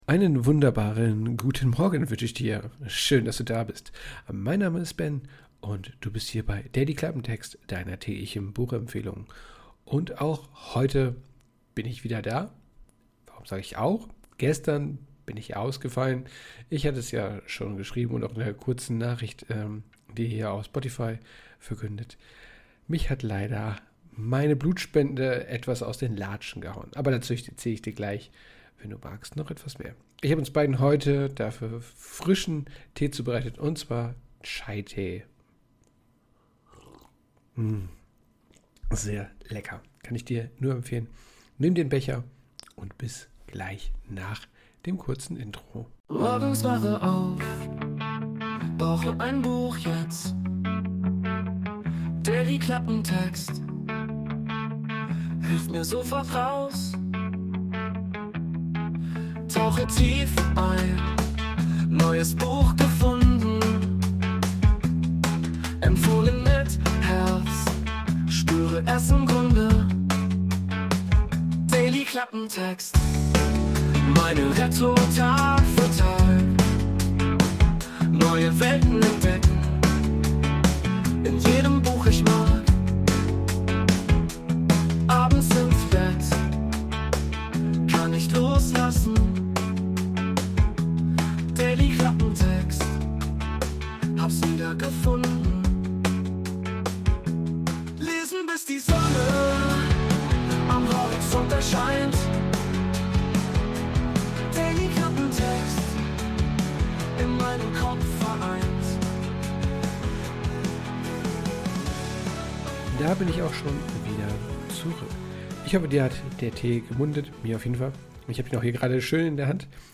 Hinweis 2: Das Intro wurde ebenfalls mit einer KI generiert.